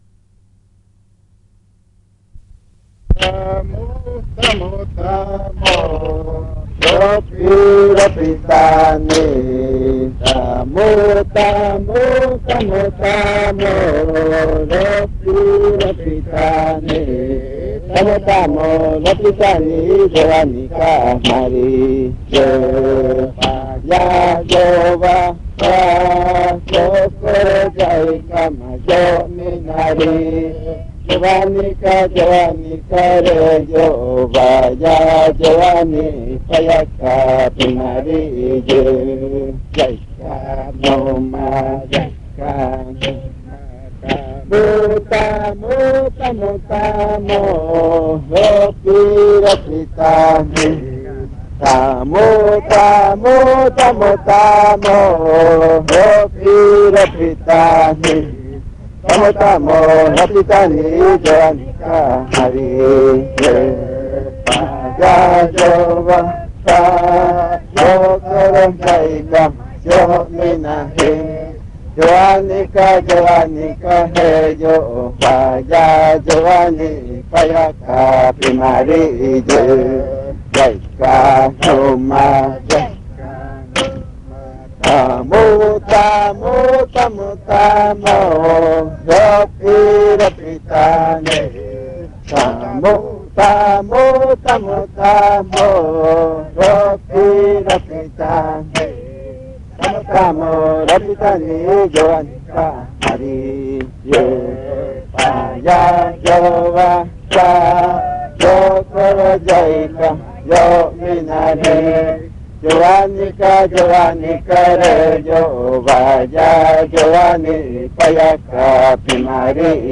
Canciones del baile
El audio incluye los lados A y B del casete .
realmente se trata de cantos de Ʉjkʉtso majtsi de cosecha de piña.